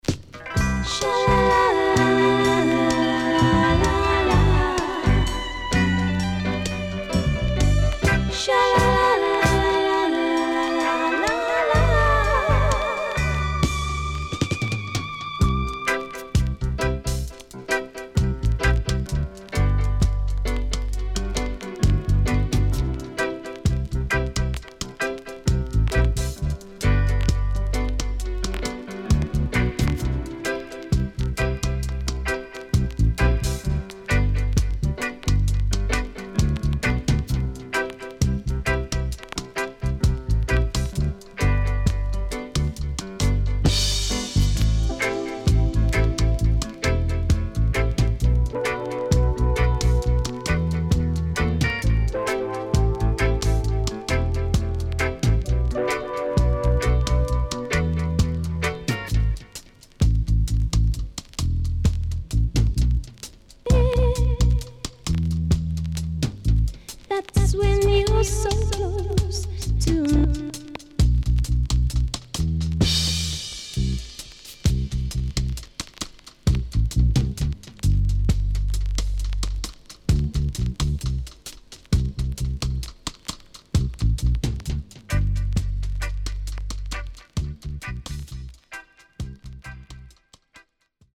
【12inch】-Color Vinyl
UK Lovers Classics
SIDE A:所々チリノイズがあり、少しプチノイズ入ります。